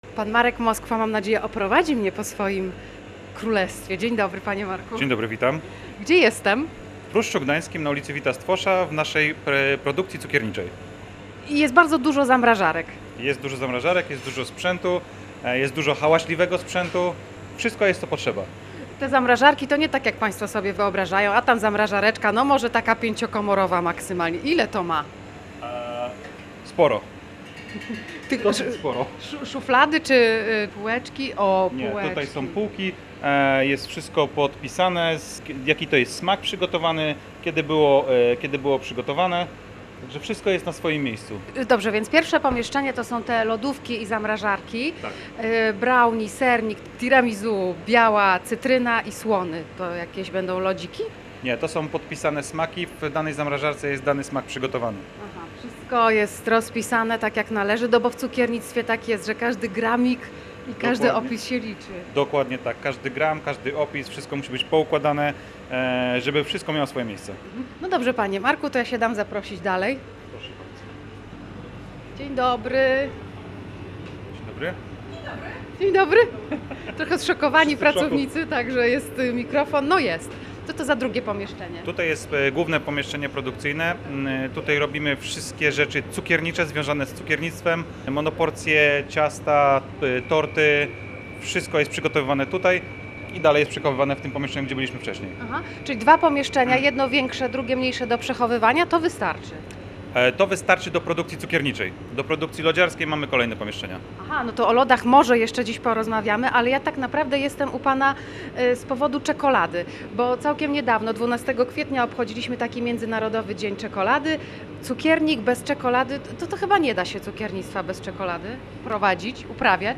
W audycji kulinarnej „Gotuj się na Weekend”